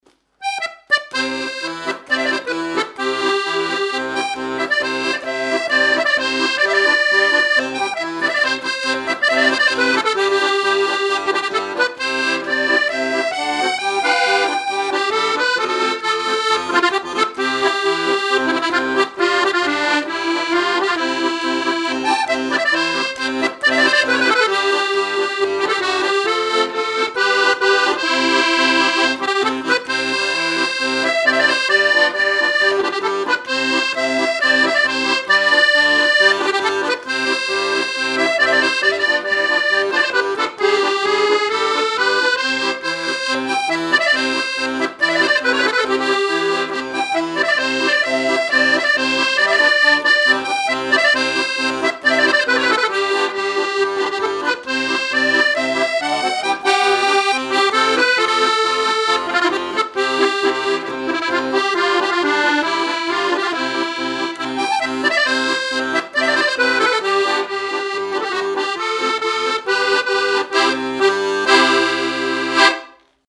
danse : marche
Genre strophique
Pièce musicale inédite